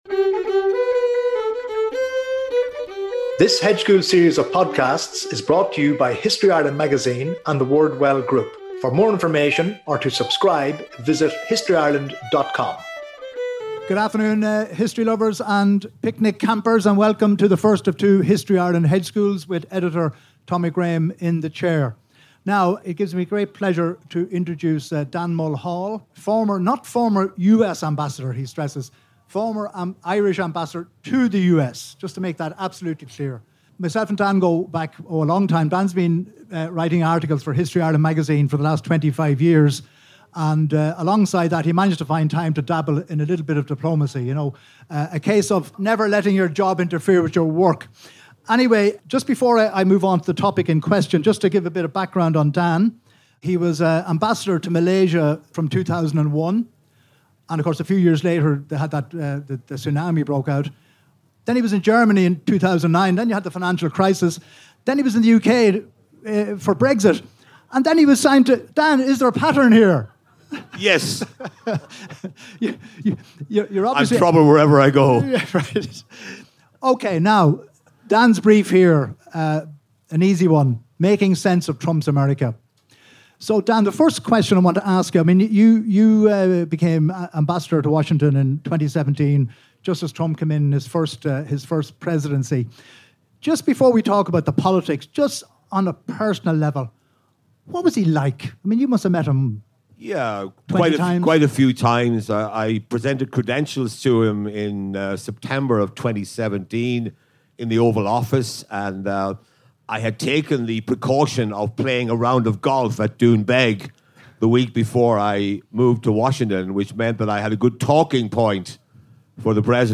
Recorded live on Friday 29 August ’25 @ the Electric Picnic